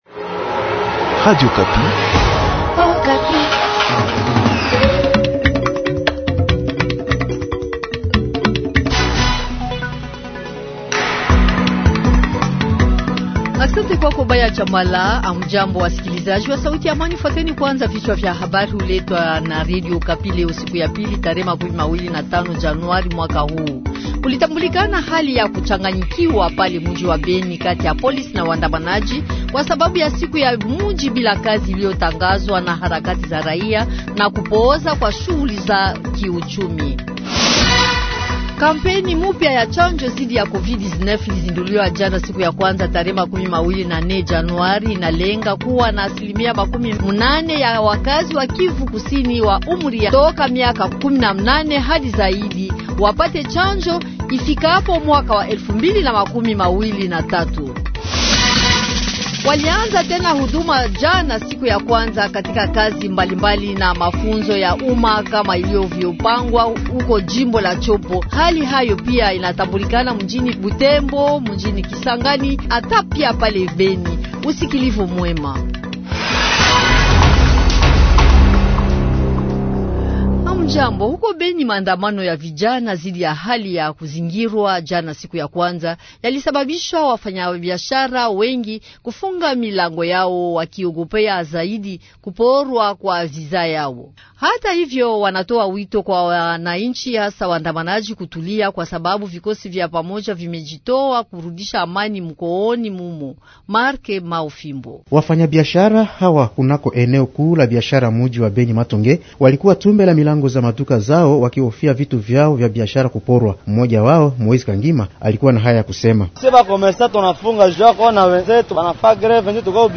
JOURNAL DU MATIN